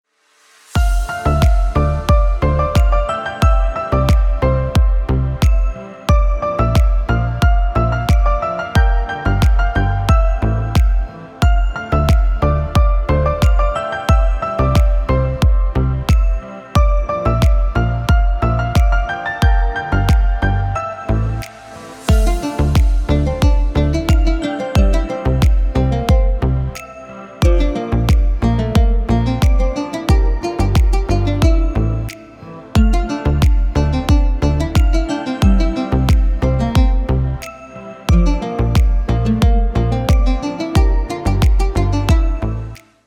• Качество: Хорошее
• Категория: Красивые мелодии и рингтоны